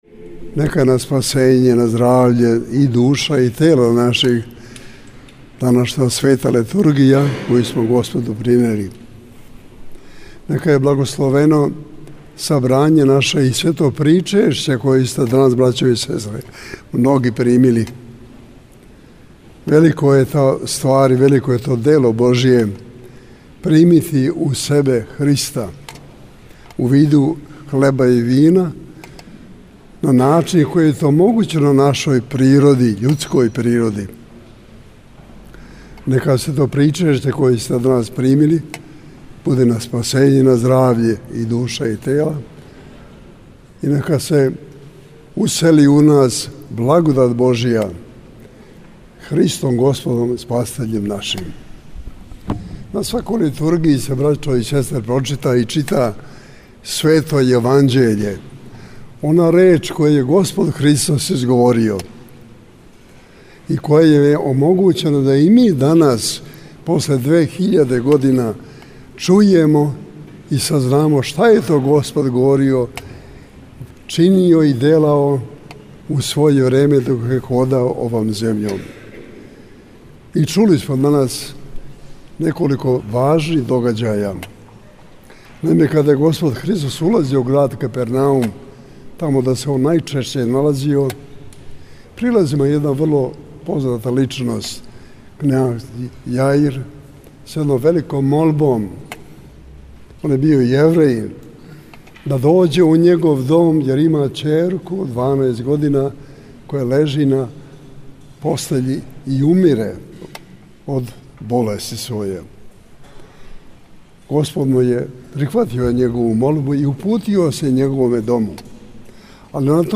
Његова Светост Патријарх српски Г. Иринеј, служио је данас, у недељу 01. децембра 2019. године, Свету Архијерејску Литургију у Саборној цркви у Београду.